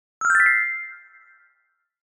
Download Twinkle sound effect for free.
Twinkle